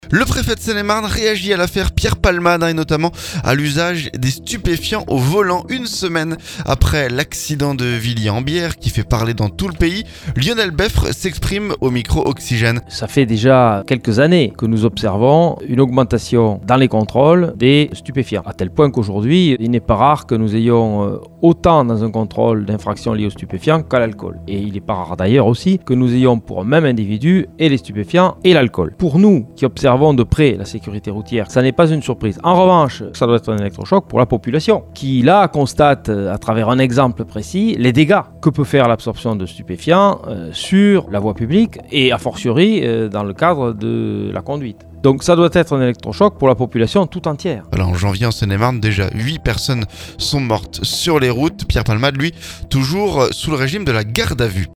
Et notamment à l'usage des stupéfiants au volant. Une semaine après l'accident de Villiers en Bière, qui fait parler dans tout le pays, Lionel Beffre s'exprime au micro Oxygène.